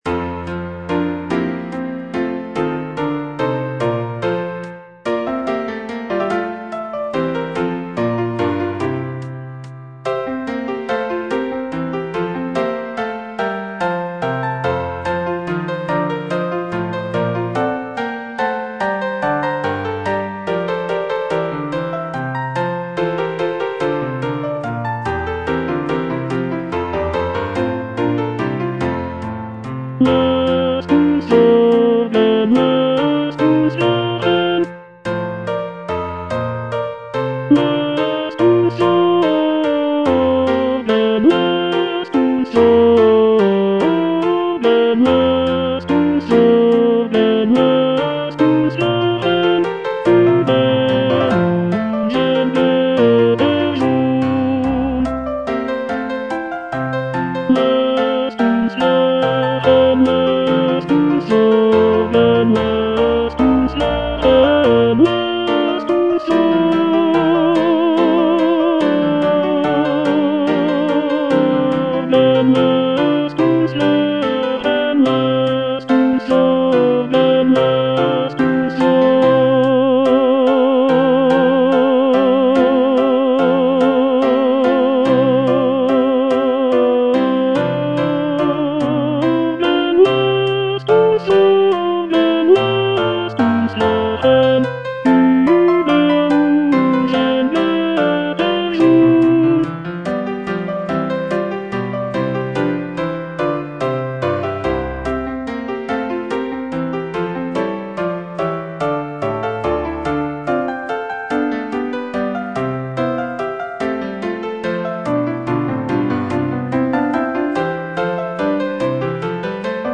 Cantata
Tenor (Voice with metronome) Ads stop